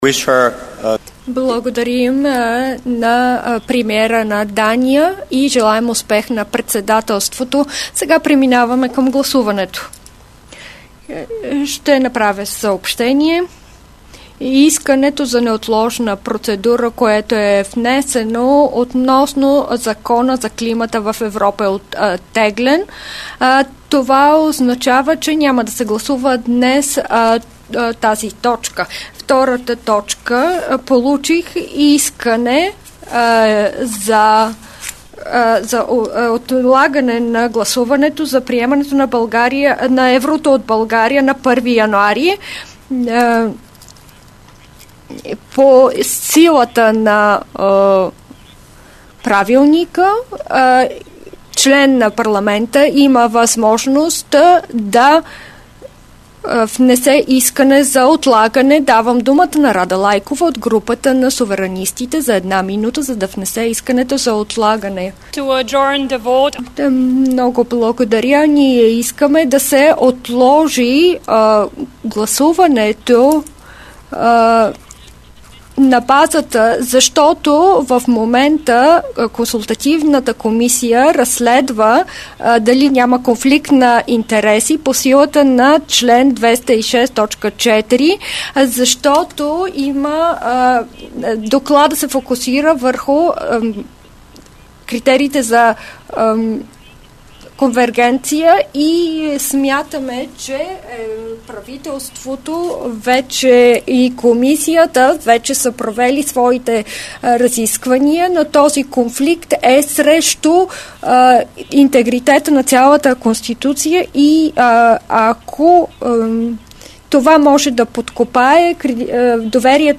12.30 - Заседание на ЕП.
Директно от мястото на събитието